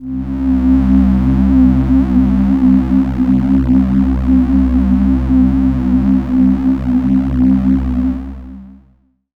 Amb1n2_a_synth_c_drone.wav